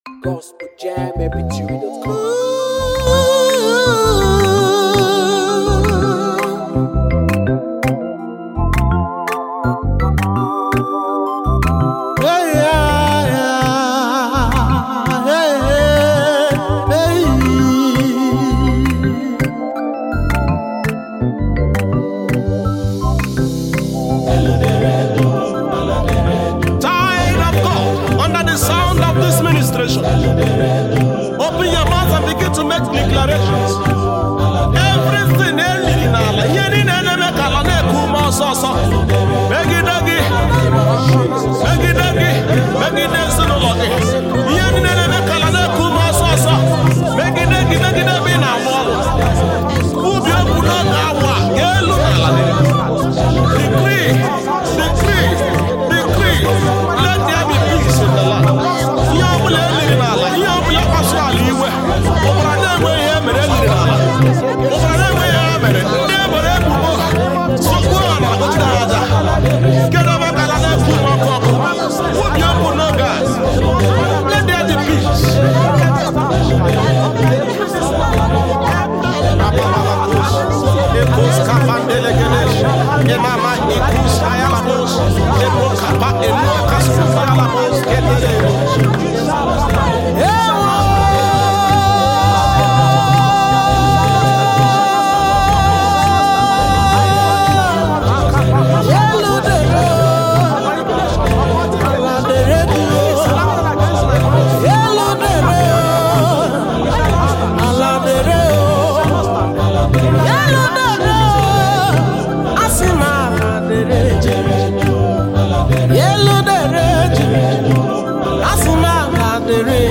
warfare spiritual song